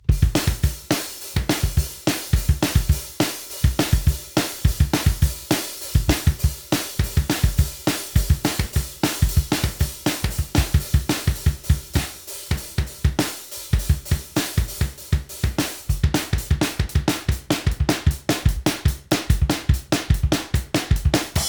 navy_drums.flac